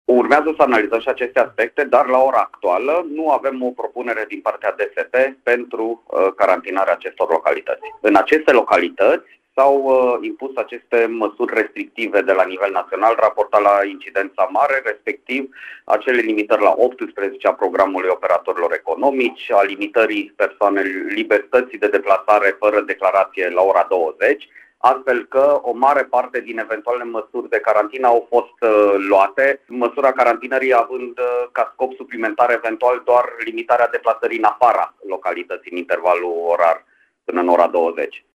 Prefectul de Timiș, Zoltan Nemeth, a mai precizat, la Radio Timișoara, că deocamdată DSP nu a transmis o recomandare de carantinare a localităților Orțișoara, Pădureni, Sânmihaiu Român sau Jebel, care au rate mari de infecare cu coronavirus.